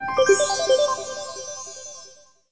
FolderOpen.wav